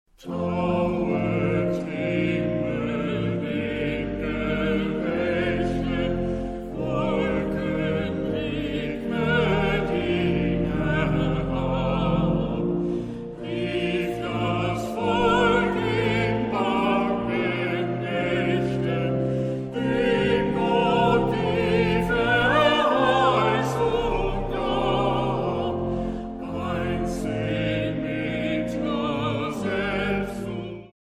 Festliche Advents- und Weihnachtsmusik
Vocal- und Orgelmusik Neuerscheinung 2010